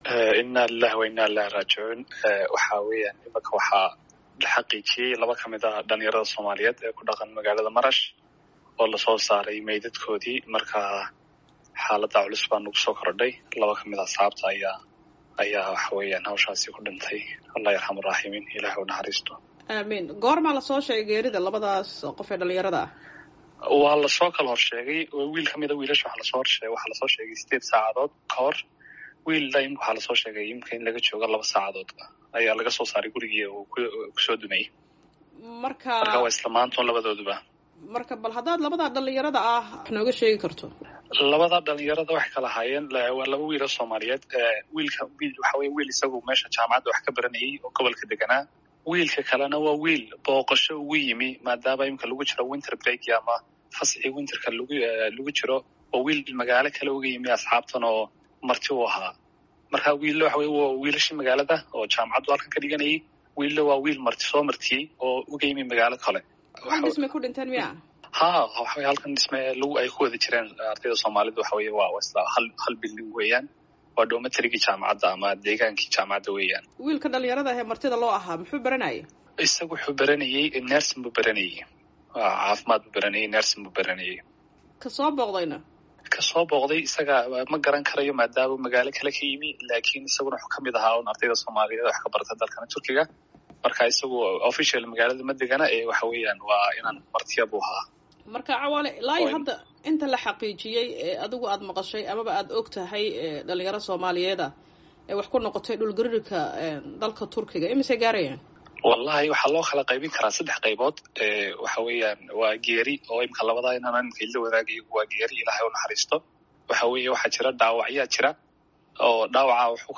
Wareysi: Xaaladda Soomaalida Turkey kadib dhul-gariirkii ku dhuftay